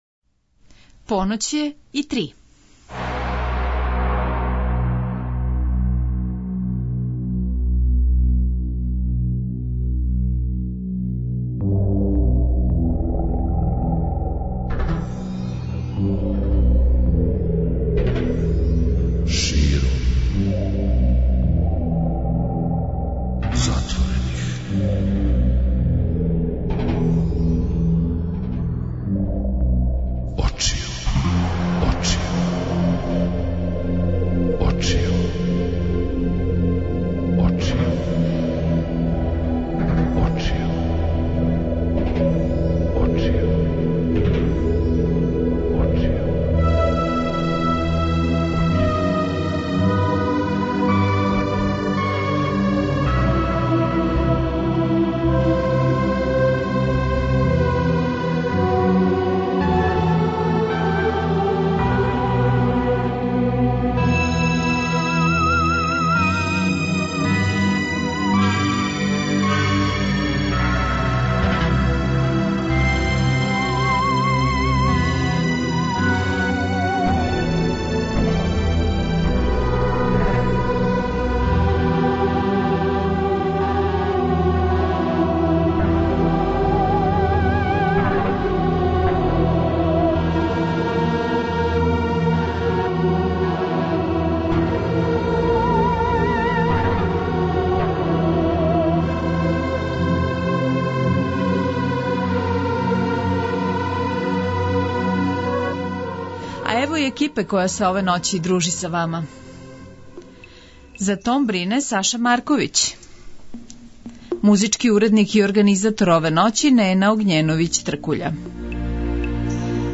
слушаоци ће имати прилике да чују и неке од његових песама, али ће говорити и о својој каријери предавача.